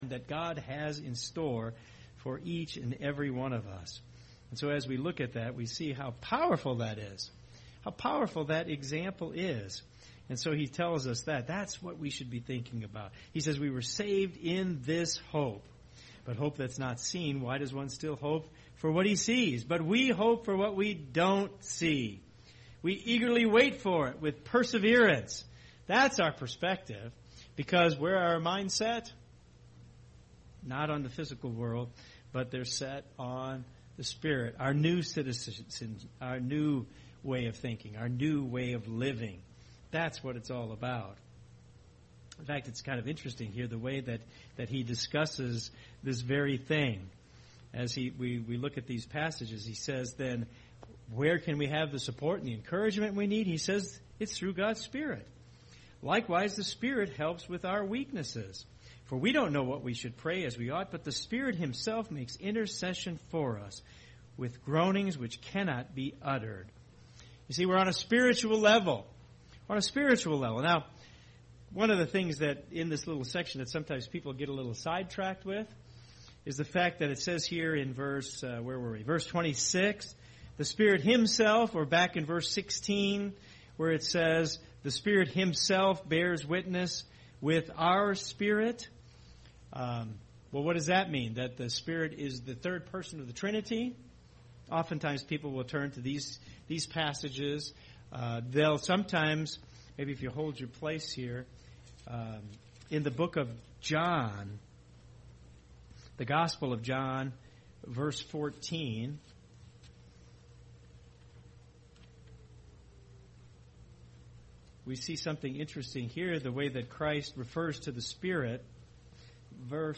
ABC Continuing Education Sampler UCG Sermon Transcript This transcript was generated by AI and may contain errors.